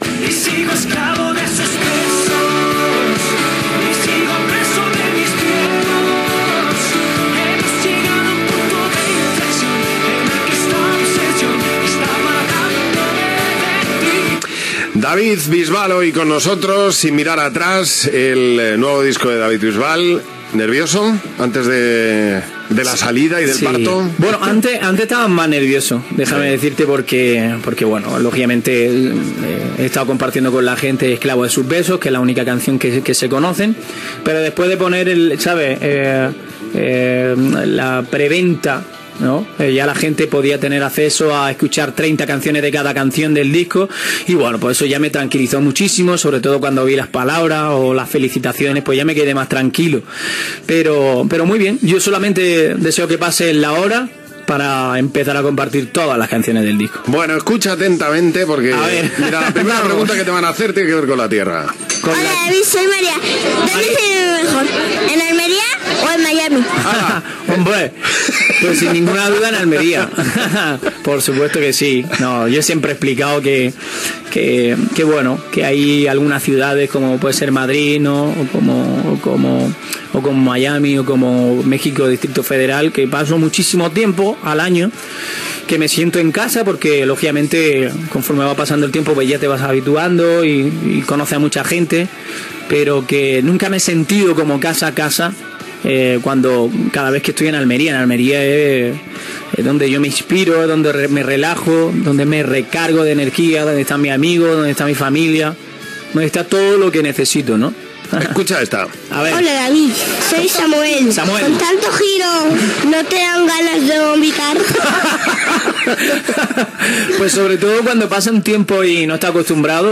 Entrevista al cantant David Bisbal que també contesta les preguntes fetes per nens i nenes
Entreteniment
FM